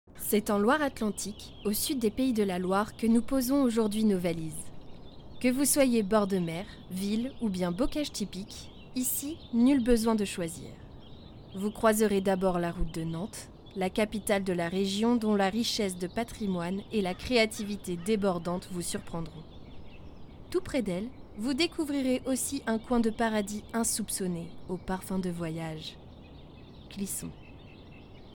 Voix Reportage